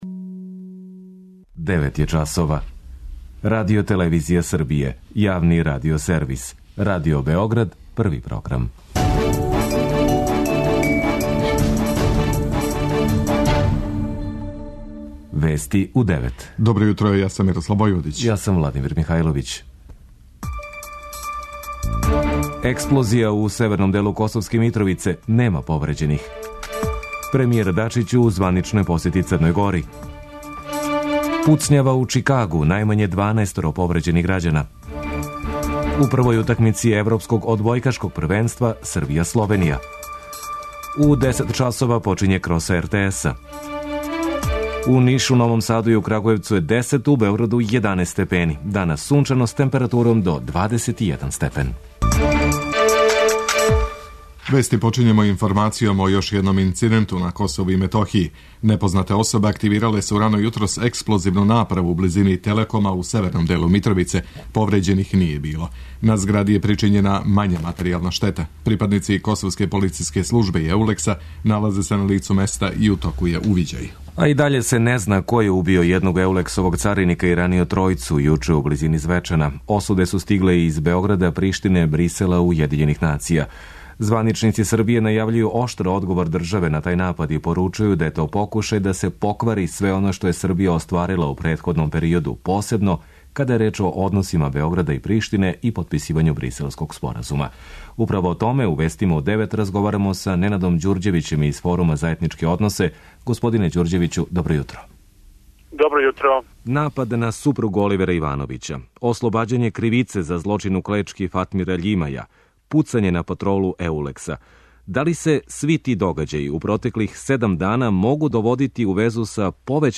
Вести у 9
преузми : 10.08 MB Вести у 9 Autor: разни аутори Преглед најважнијиx информација из земље из света.